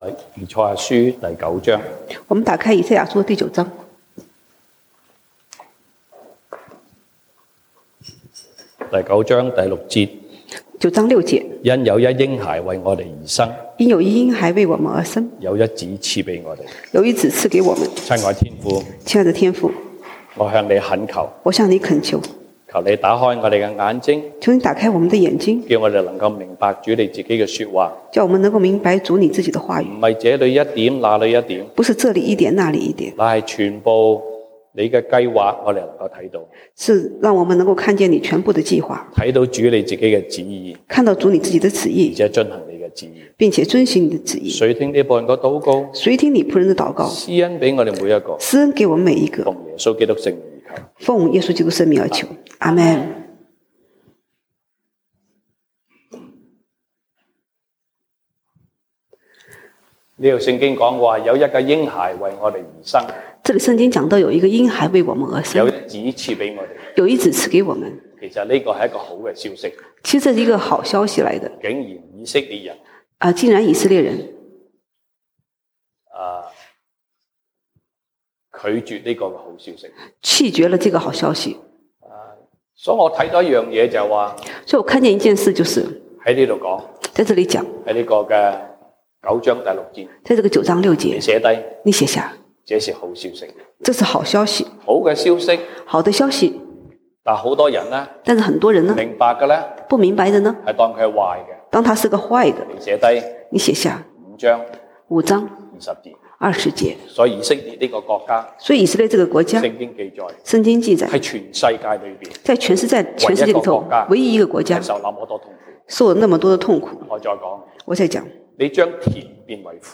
西堂證道(粵語/國語) Sunday Service Chinese: 以賽亞書 Isaiah 9:6-7